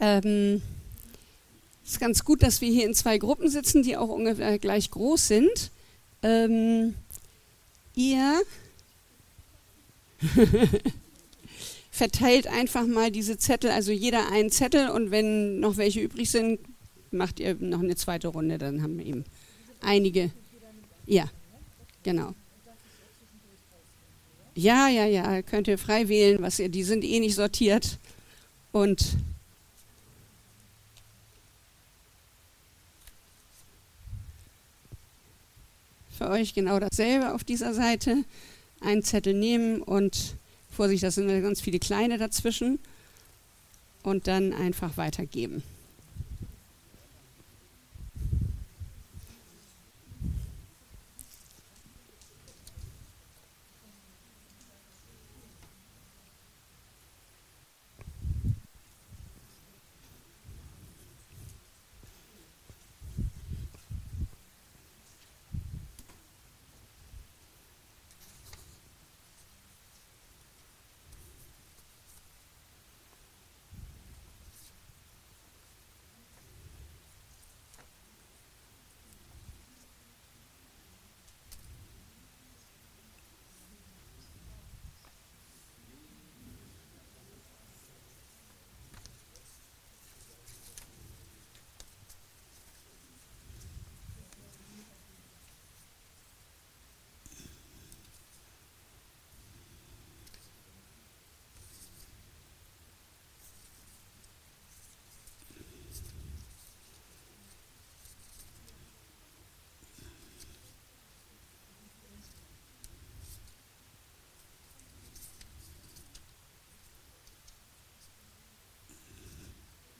Gottesdienst: Sonntag